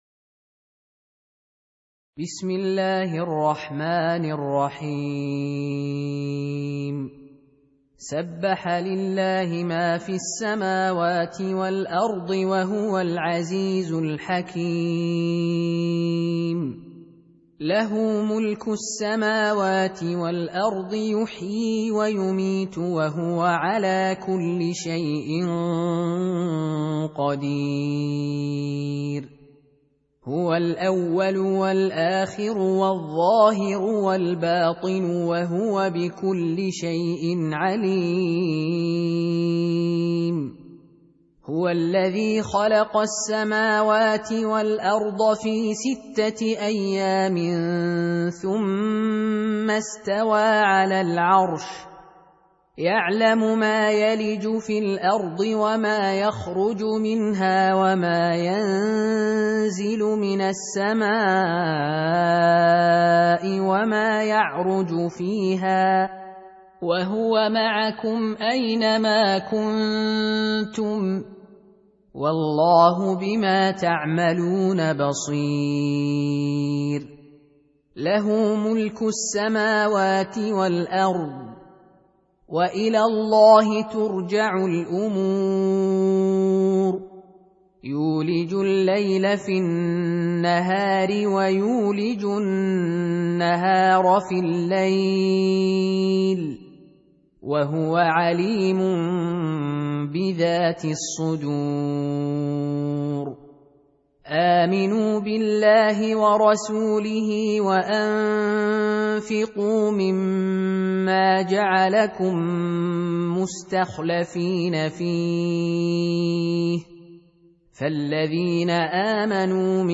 Surah Repeating تكرار السورة Download Surah حمّل السورة Reciting Murattalah Audio for 57. Surah Al-Had�d سورة الحديد N.B *Surah Includes Al-Basmalah Reciters Sequents تتابع التلاوات Reciters Repeats تكرار التلاوات